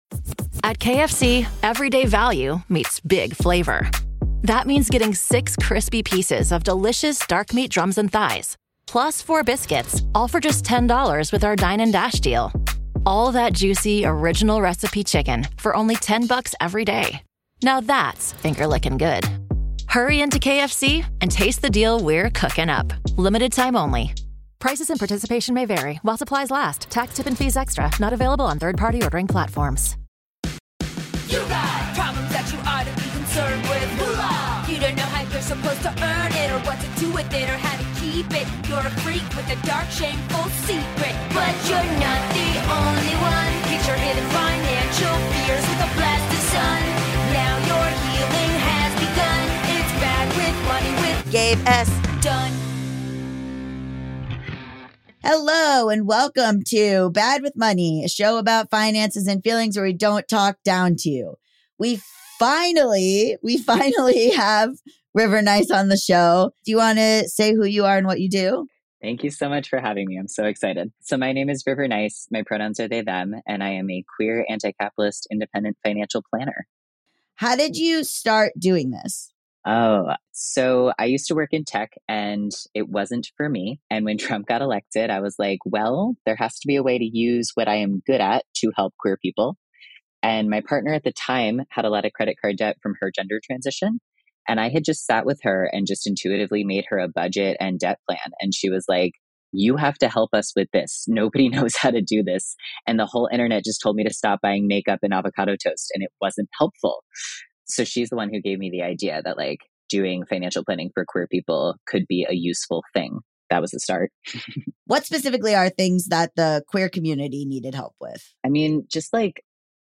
This was a really enlightening, vulnerable and informative interview. We get into ethical investing, break ups, gender affirming care, and queer adult timelines.